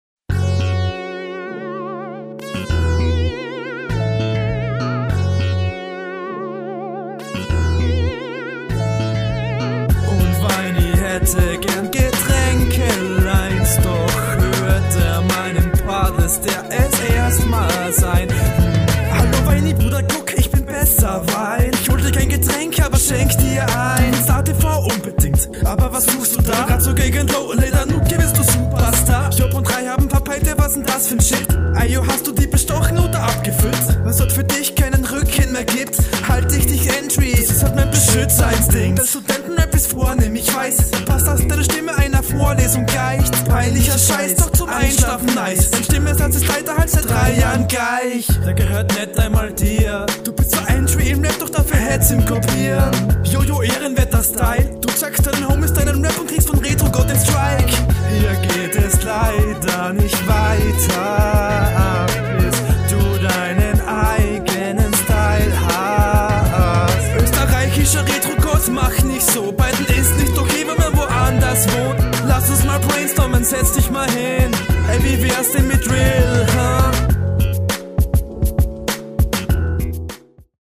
Ich fand die Soundmische ziemlich schlecht dennoch ist der flow zumteil sehr geil ich feier …
Schon wieder so eine Gesangsline die meinen Gehörgang auseinander nimmt.
Beat cool. Stimme präsenter mischen bitte.